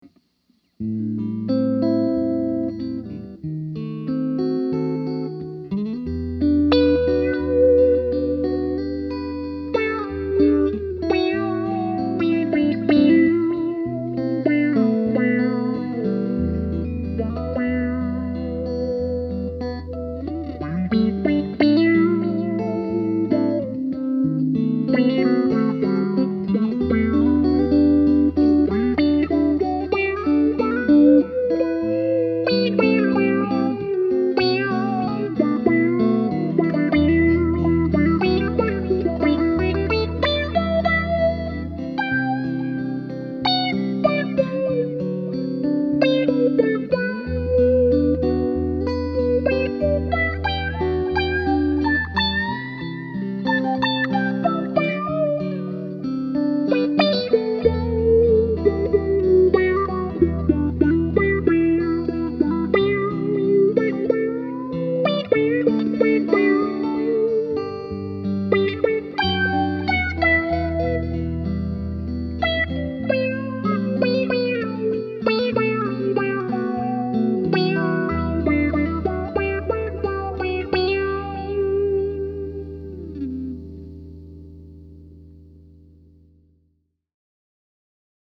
The following clips were all played with my Fender American Deluxe Strat into my trusty Roland Cube 60, set to a clean “Blackface” setting.
Second is another lead track, but I added in some reverb and delay to give the tone some space.